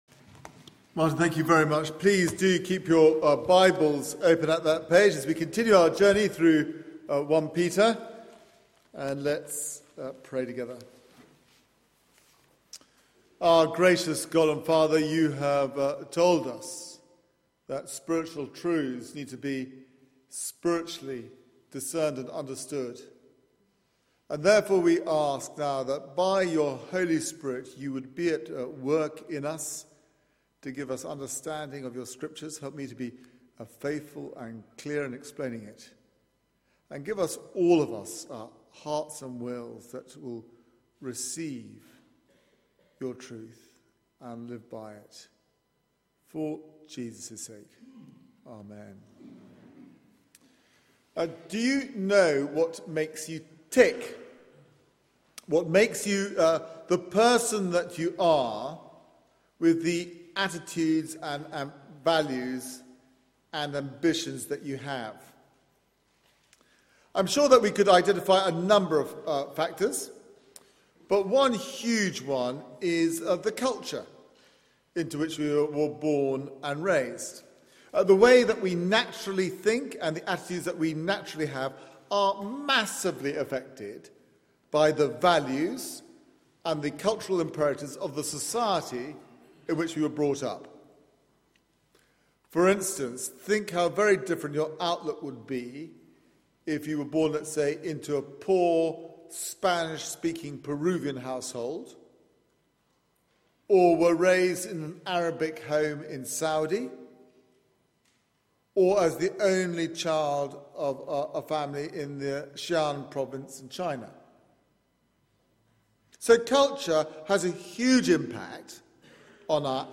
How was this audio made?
Media for 9:15am Service on Sun 02nd Feb 2014